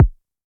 RDM_TapeA_SY1-Kick04.wav